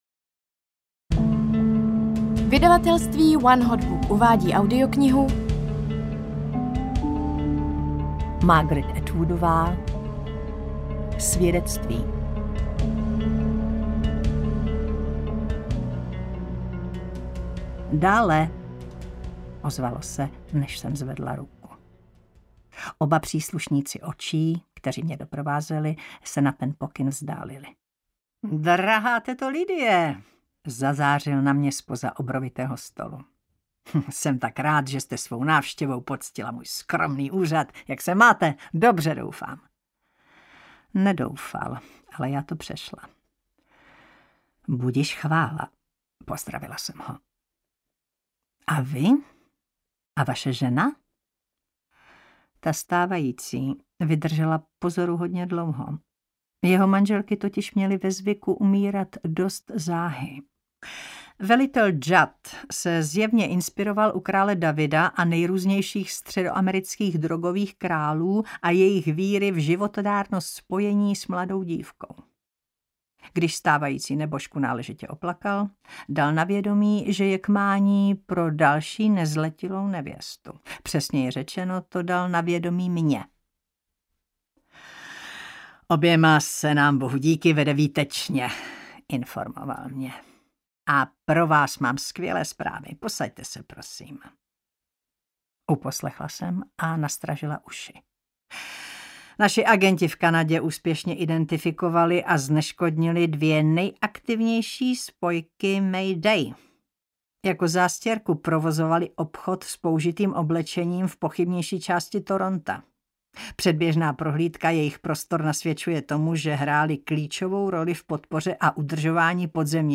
Svědectví audiokniha
Ukázka z knihy